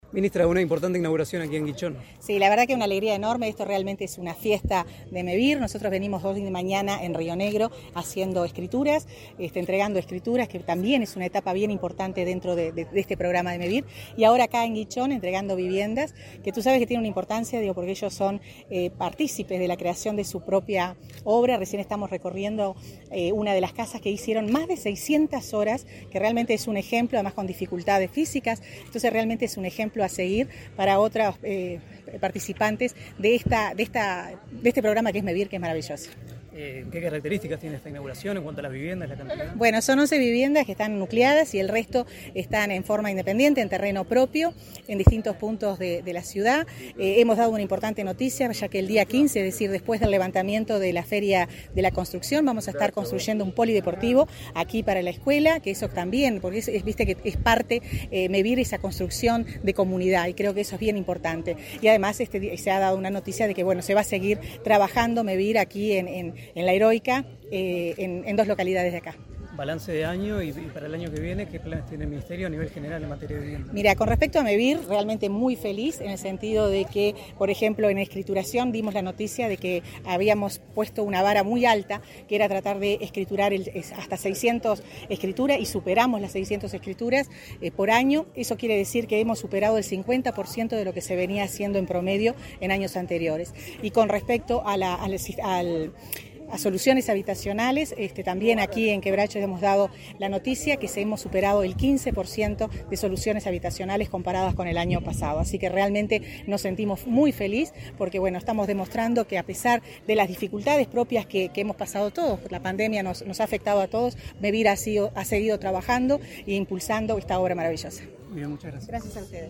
Declaraciones a la prensa de la ministra Irene Moreira